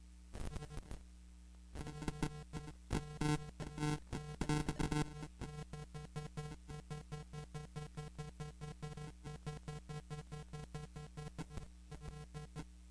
短波电台 " shtwv min 4 radnoiz 26
描述：另一个简短的短波无线电噪音样本，声音像快速的摩斯密码。
标签： 环境 大气 噪声 射频 短波
声道立体声